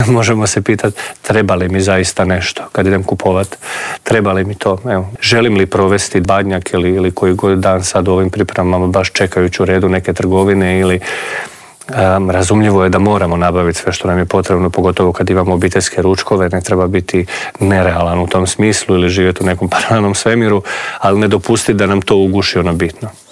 U Intervjuu Media servisa